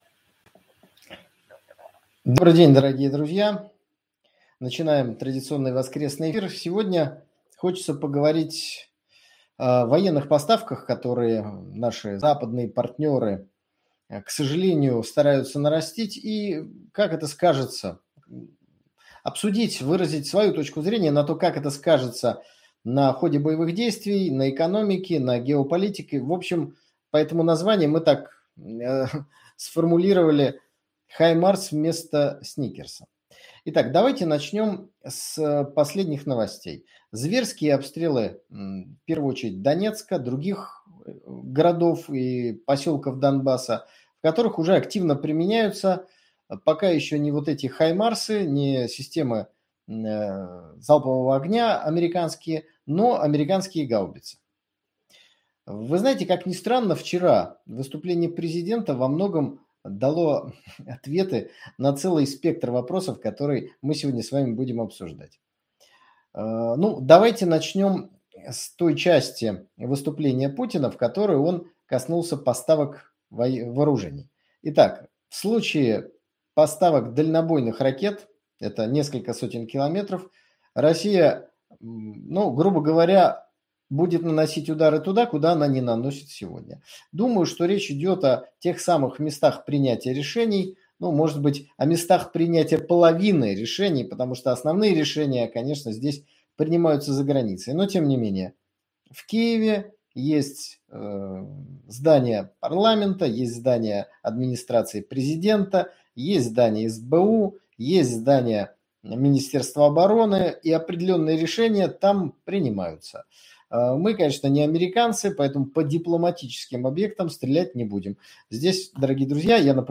В очередном прямом эфире речь зашла о поставка США наступательного вооружения, в т.ч. РСЗО HiMARS киевскому режиму. Поговорили о том, как это скажется на ходе спецоперации, и на положении дел в целом.